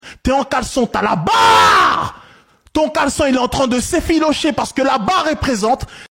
tes en calecon ta la barre Meme Sound Effect
Category: Meme Soundboard